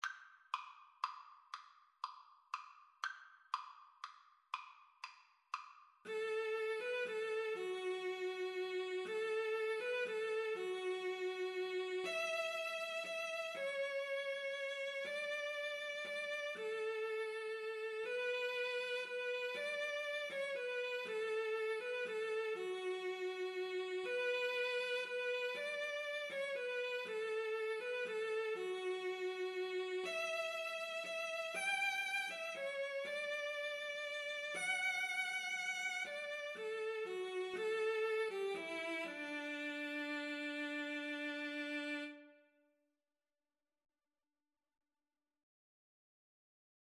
Christmas Christmas Violin-Cello Duet Sheet Music Silent Night Duet
D major (Sounding Pitch) (View more D major Music for Violin-Cello Duet )
6/8 (View more 6/8 Music)
Violin-Cello Duet  (View more Easy Violin-Cello Duet Music)
Traditional (View more Traditional Violin-Cello Duet Music)